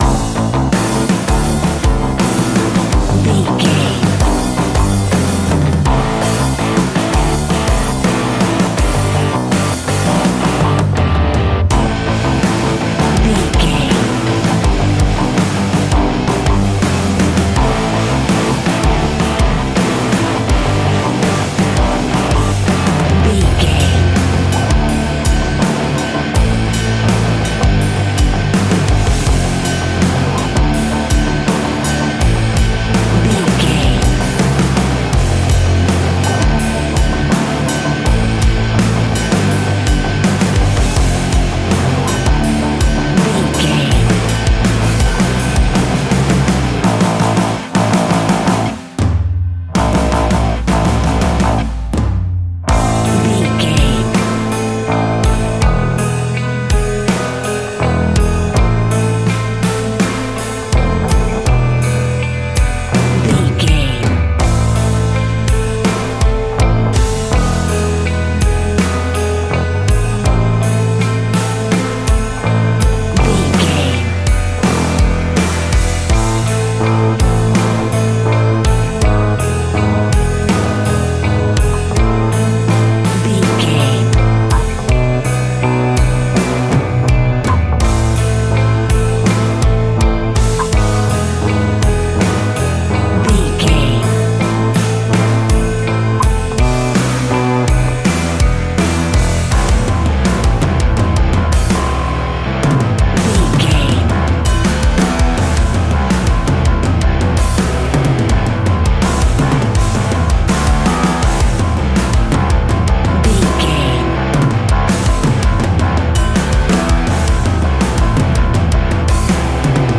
Ionian/Major
D♭
hard rock
guitars
instrumentals